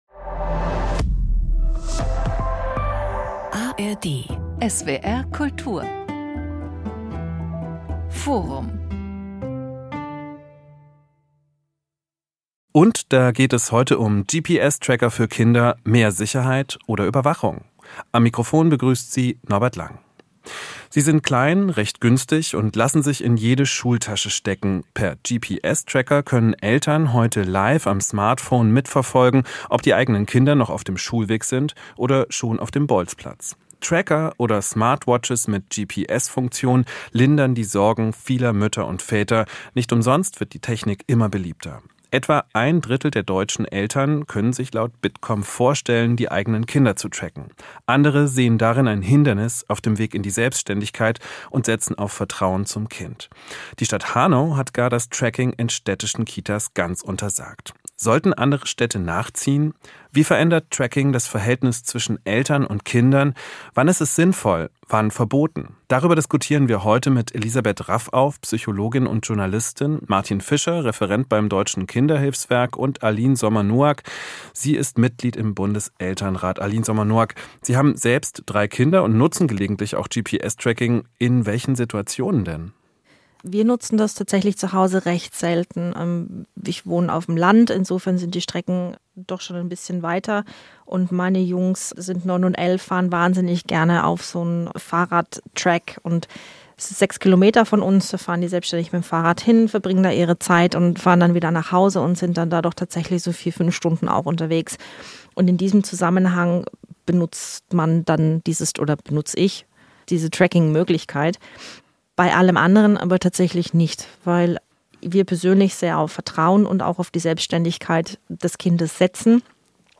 diskutiert